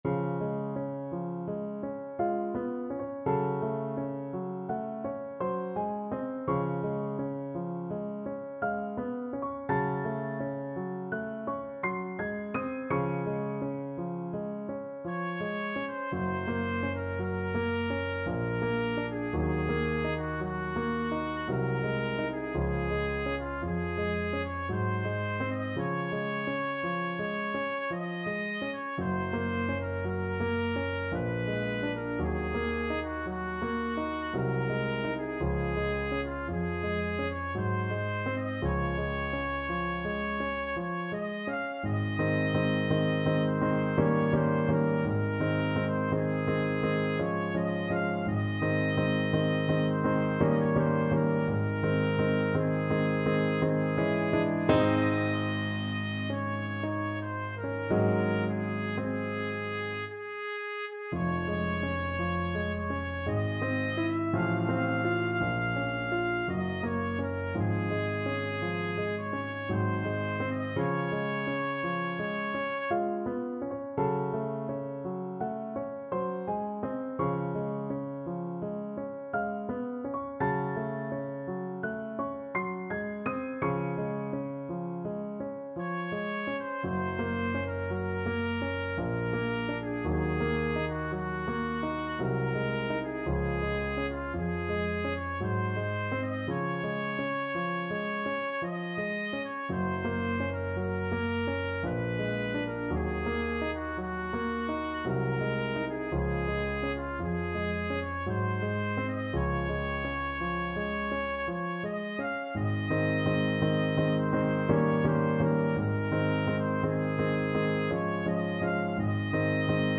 Trumpet version
~ = 56 Ziemlich langsam
3/4 (View more 3/4 Music)
Classical (View more Classical Trumpet Music)